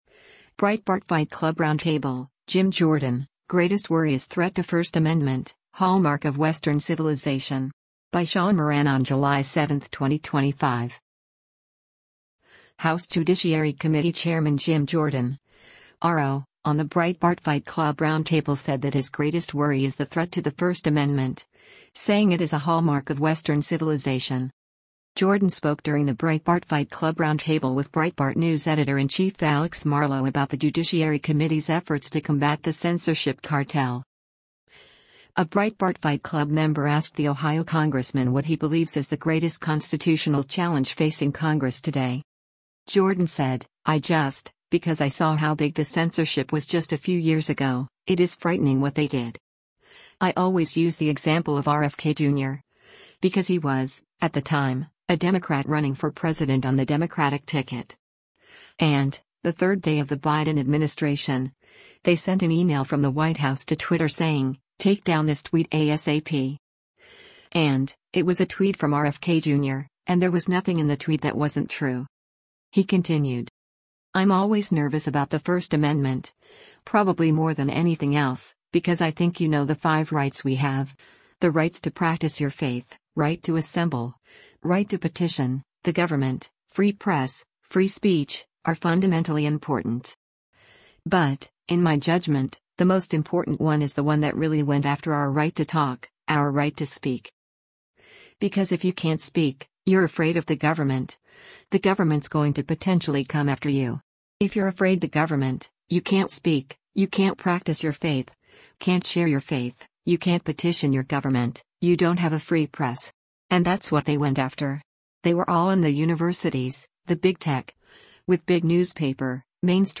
A Breitbart Fight Club member asked the Ohio congressman what he believes is the “greatest constitutional challenge facing Congress today.”